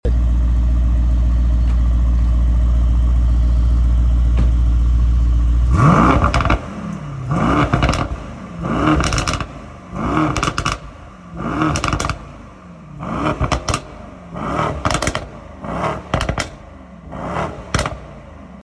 Mercedes E63s Amg Stage 1 Sound Effects Free Download